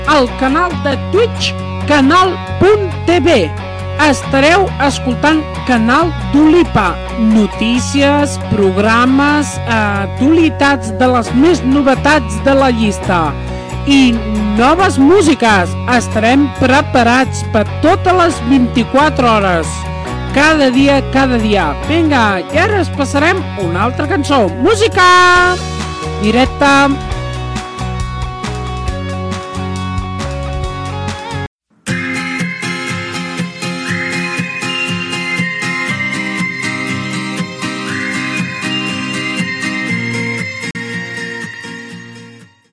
Musical
Indentificació de la ràdio a Twitch i tema musical